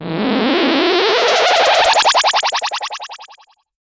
Attention Sound Effects - Free AI Generator & Downloads
style-cartoonish-realisti-tf6ljwp3.wav